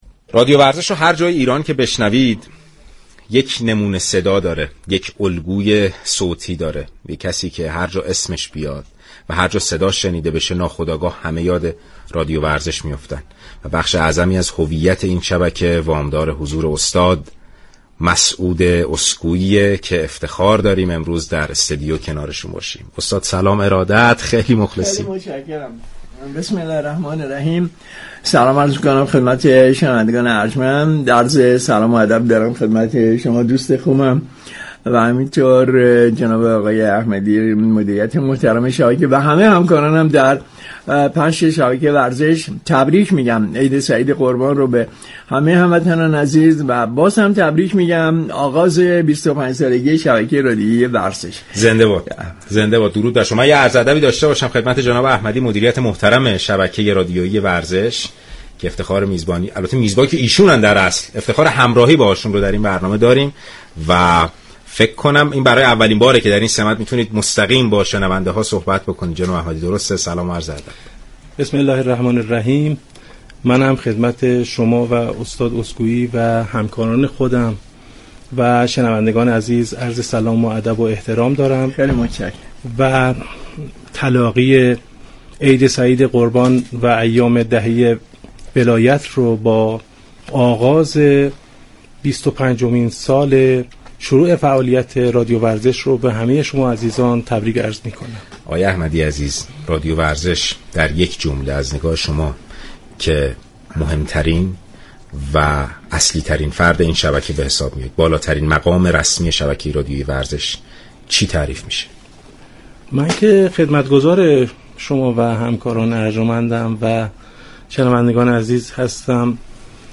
مراسم جشن تولد 25 سالگی رادیو ورزش و عید سعید قربان به صورت زنده پخش شد.